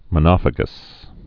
(mə-nŏfə-gəs)